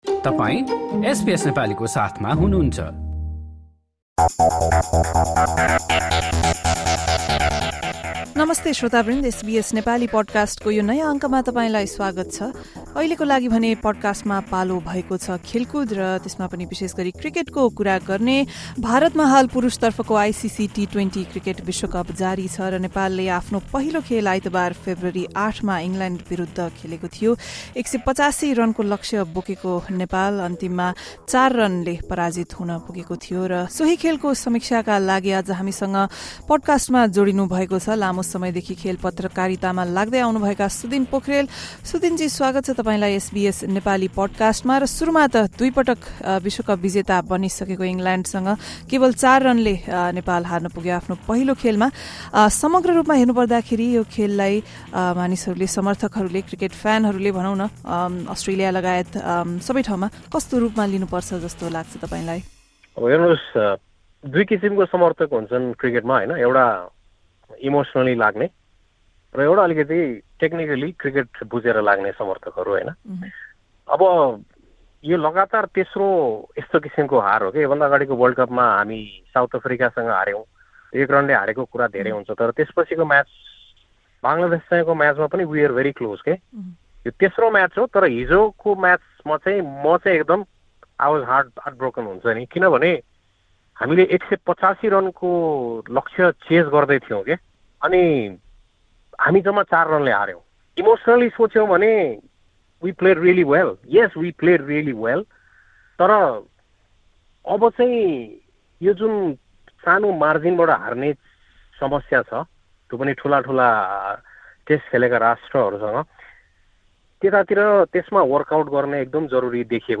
sports analyst